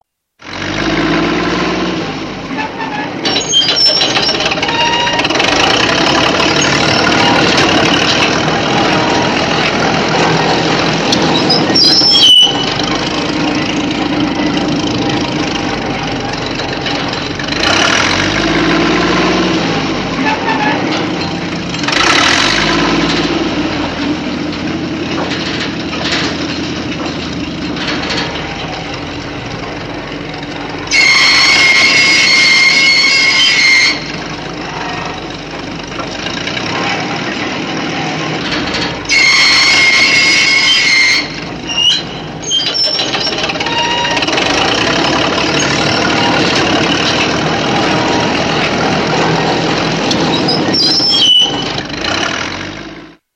Bulldozer - Бульдозер (Трактор)
Отличного качества, без посторонних шумов.
175_bulldozer.mp3